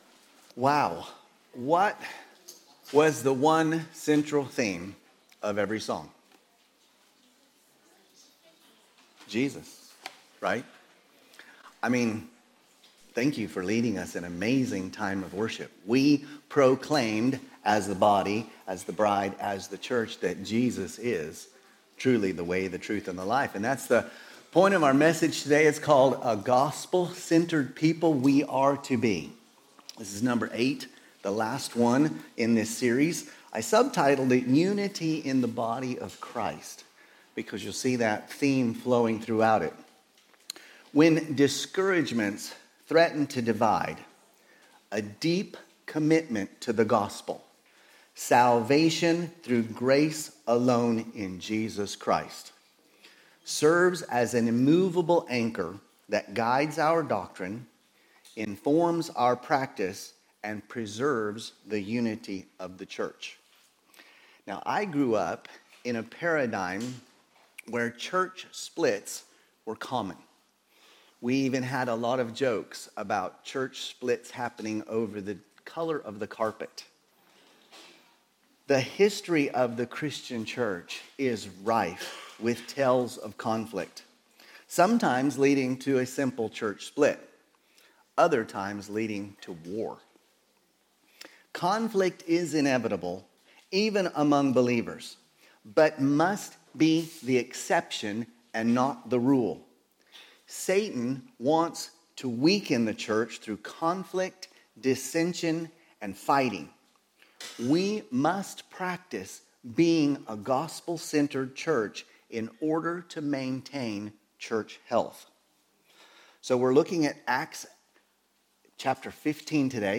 Sermons – Immanuel Baptist Church | Madrid